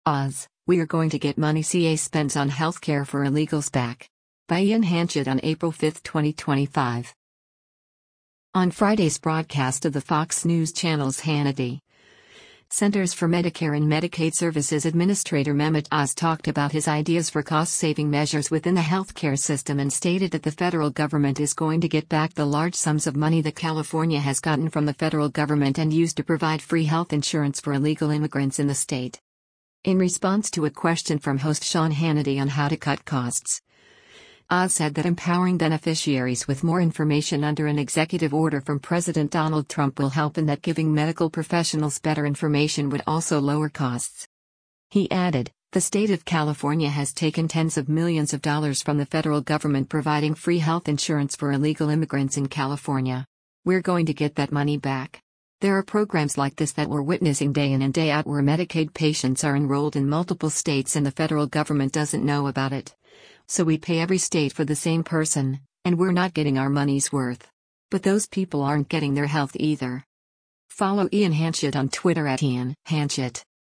On Friday’s broadcast of the Fox News Channel’s “Hannity,” Centers for Medicare and Medicaid Services Administrator Mehmet Oz talked about his ideas for cost-saving measures within the healthcare system and stated that the federal government is going to get back the large sums of money that California has gotten from the federal government and used to provide free health insurance for illegal immigrants in the state.